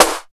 28 SNARE 3.wav